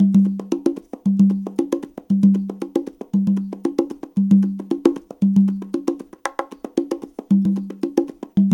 CONGA BEAT23.wav